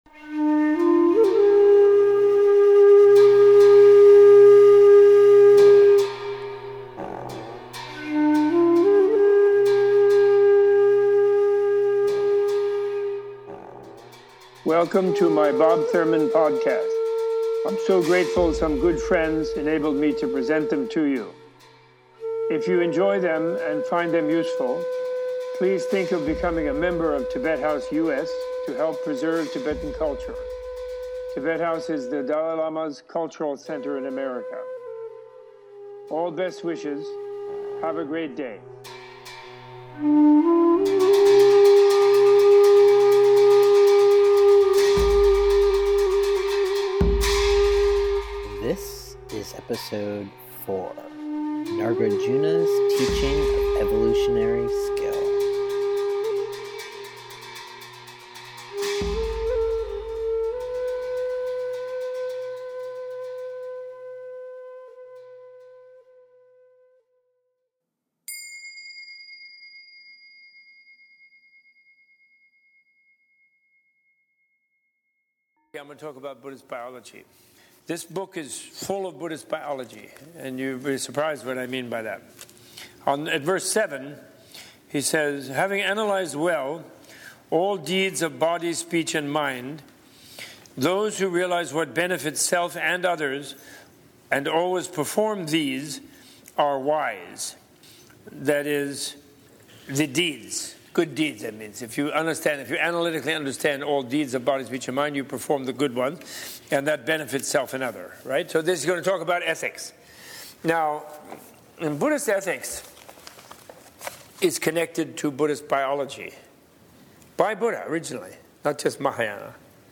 In a reading of Nāgārjuna's Jewel Rosary (Ratnāvalī), Professor Thurman discusses the skillful paths of evolutionary action performed by body, speech, and mind, presenting that these paths of evolutionary actions shape an individual's personal evolution or devolution in an evolutionary matrix similar to that of Darwin but, adding to it voluntary individual evolution through infinite multiple lives. This is an extract from Professor Thurman's Spring 2013 lecture series at Tibet House US - titled : A Banquet of Sutras and Shastras.